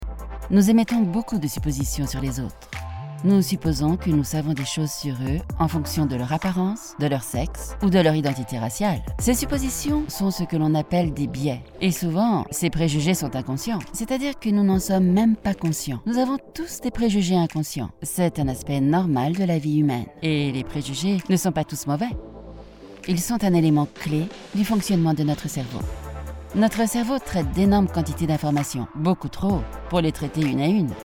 Nouvelle démo vidéo
Démos Voix off